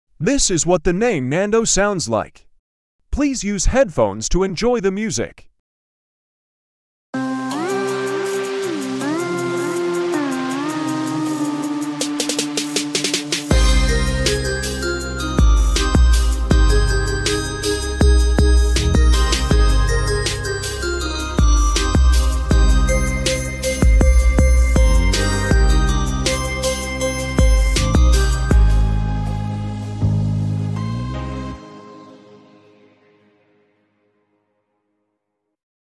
How the name Nando sounds like as midi art.